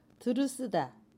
Dialect: Jeju-si